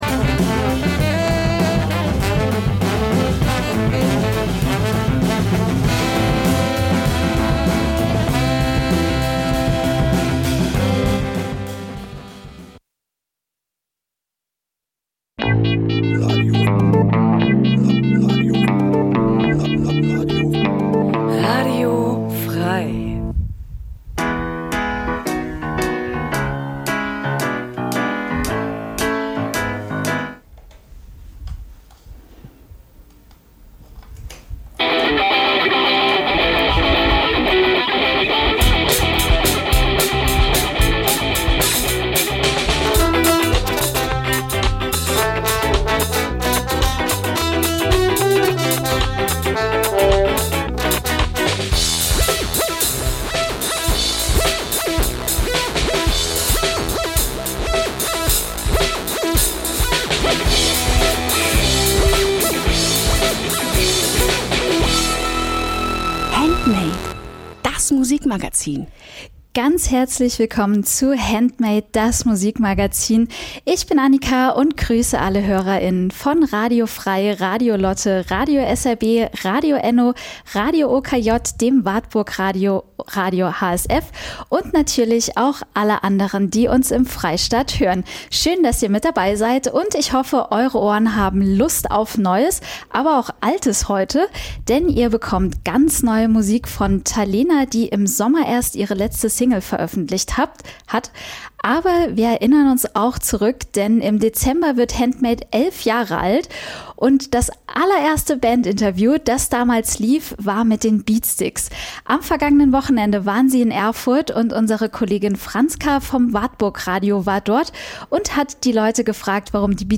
Jeden Donnerstag stellen wir euch regionale Musik vor und scheren uns dabei nicht um Genregrenzen. Ob Punk, Rap, Elektro, Liedermacher, oder, oder, oder � � wir supporten die Th�ringer Musikszene. Wir laden Bands live ins Studio von Radio F.R.E.I. ein, treffen sie bei Homesessions oder auf Festivals.